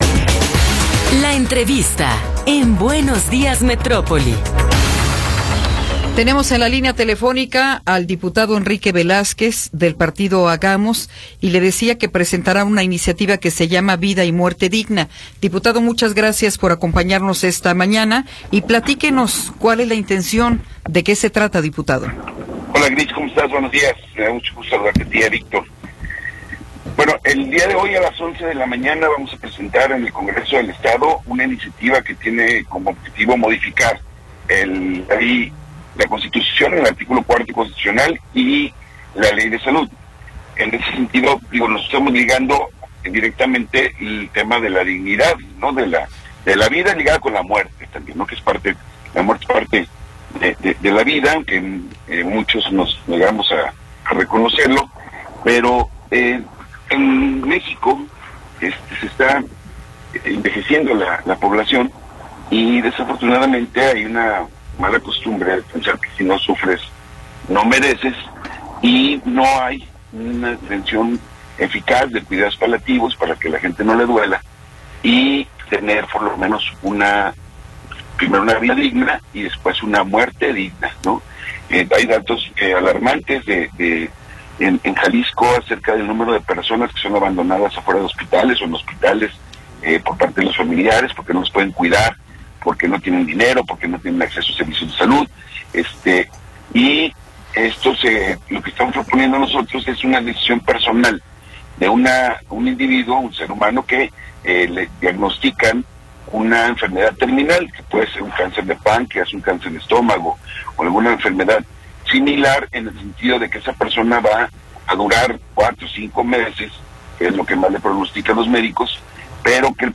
Entrevista con Enrique Velázquez González
Enrique Velázquez González, diputado local del partido Hagamos, nos habla sobre la iniciativa “Vida y Muerte Digna”.